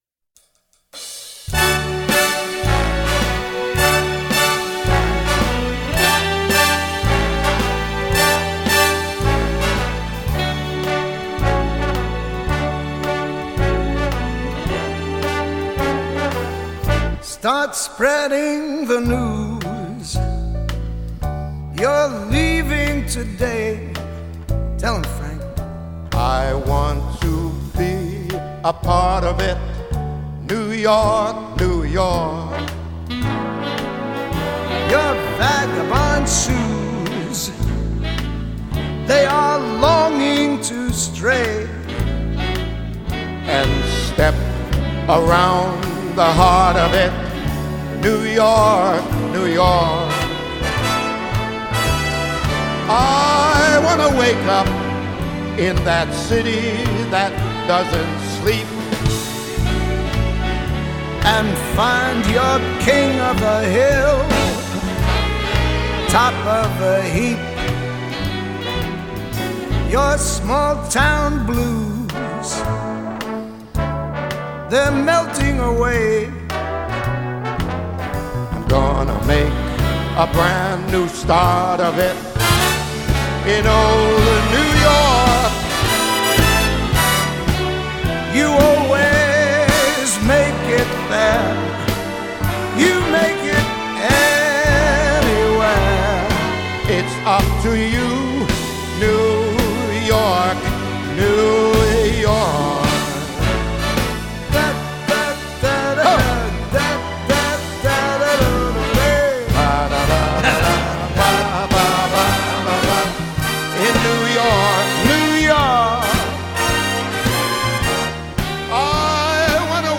而伴奏乐队则仿佛回到了大乐队时代的风格